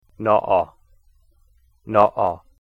naa'aa, here! take it!"-" in "uh-oh", a catch in the voice